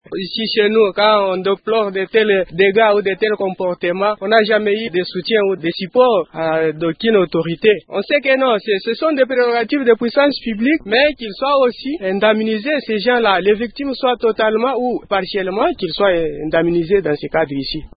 L’un d’eux témoigne :